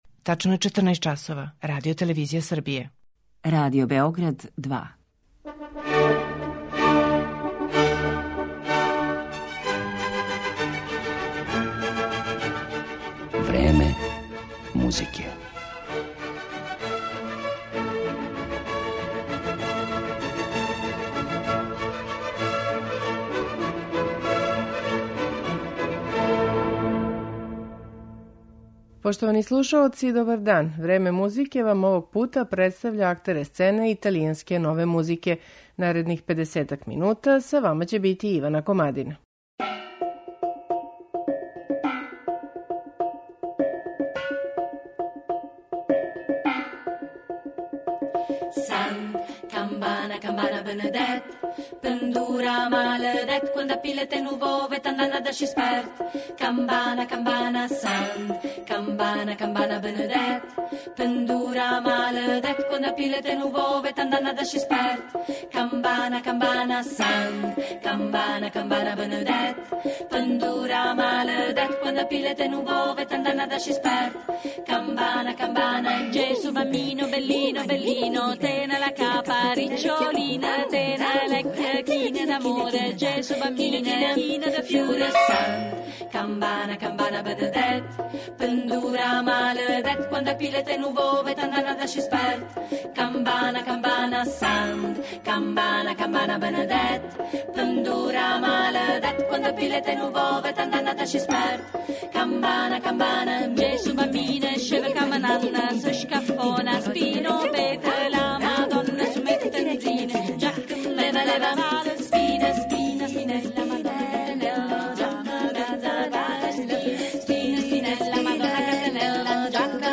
Италијанска нова музика
Женски вокални квартет